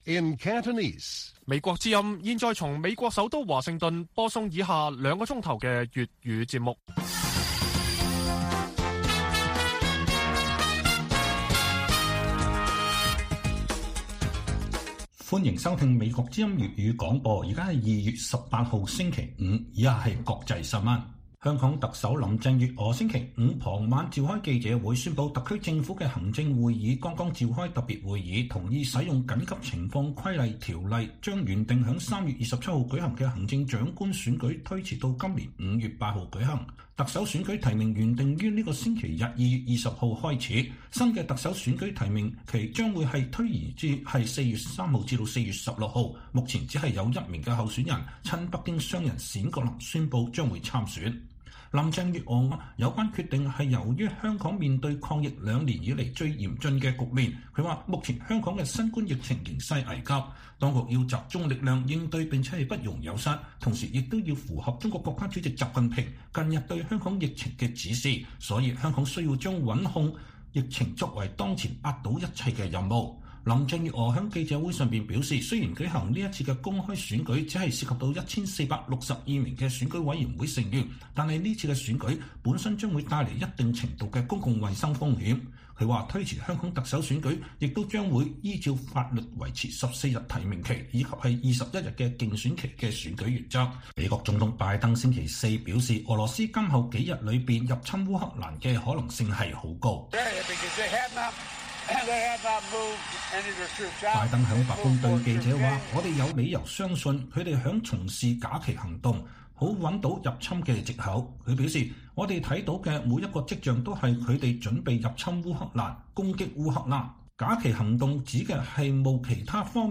粵語新聞 晚上9-10點: 香港以疫情為由宣布特首選舉延後兩個月舉行